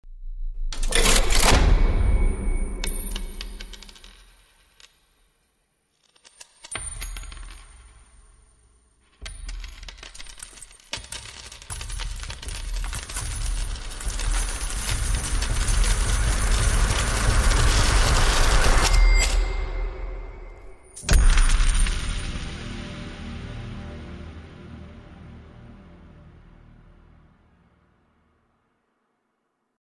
На этой странице собраны разнообразные звуки монет: от звонкого падения одиночной монеты до гула пересыпающихся денежных масс.
Шум рассыпающихся монет